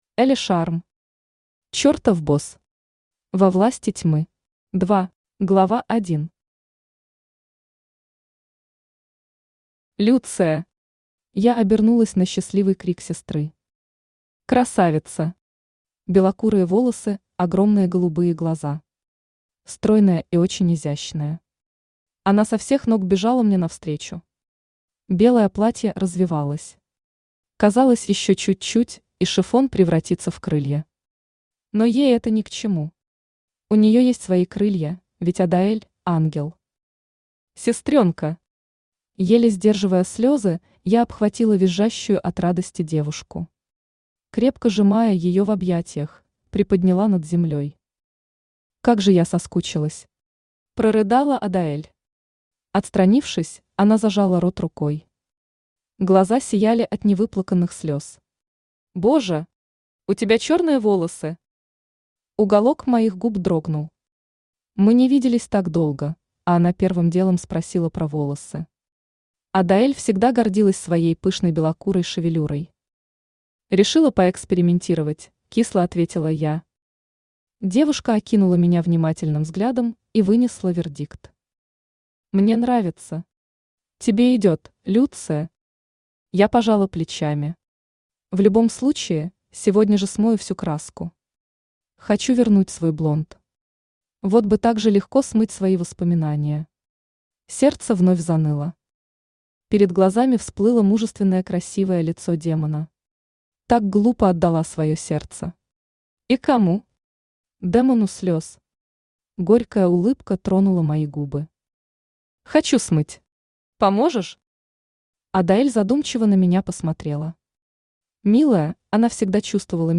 Aудиокнига Чертов босс. Во Власти тьмы. 2 Автор Элли Шарм Читает аудиокнигу Авточтец ЛитРес.